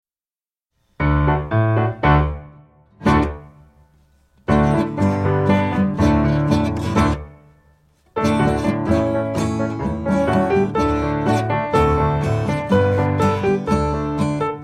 Children's Song About Frogs